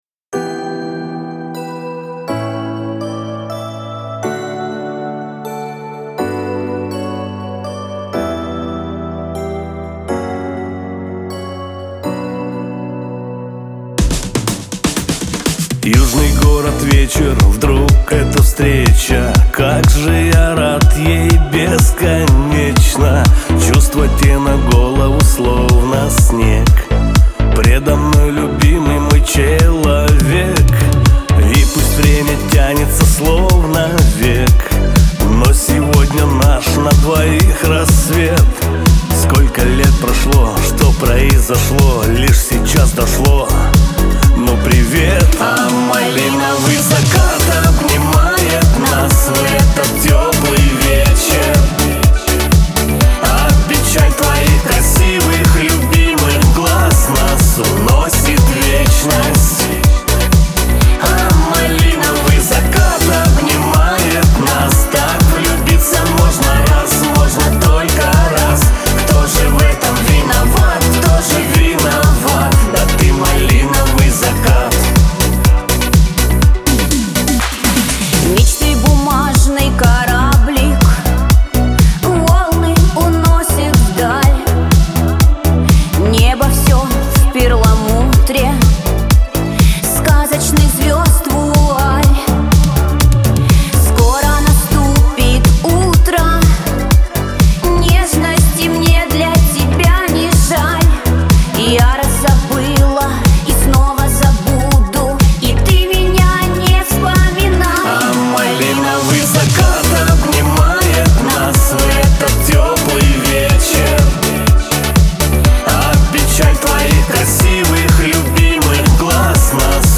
дуэт
pop
диско
эстрада